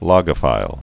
Pronunciation: